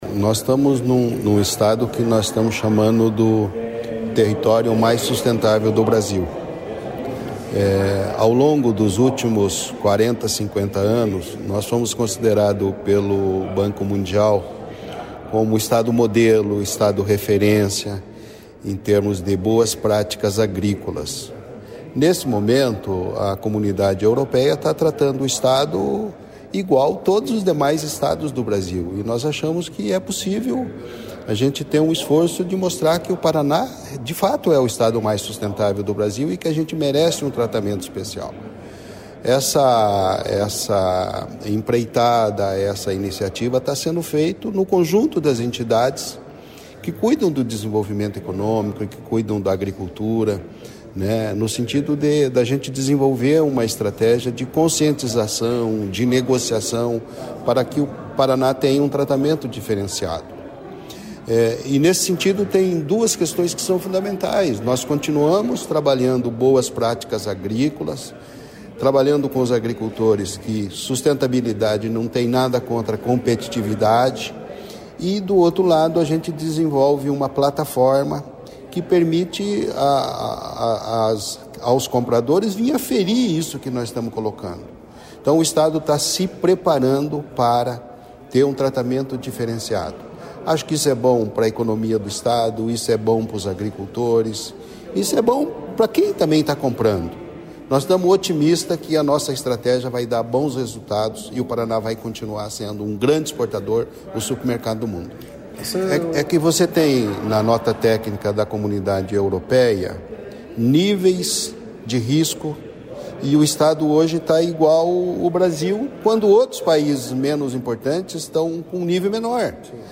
Sonora do diretor-presidente do IDR-Paraná, Natalino Avance, sobre a tecnologia usada pelo Paraná para ampliar acesso ao mercado europeu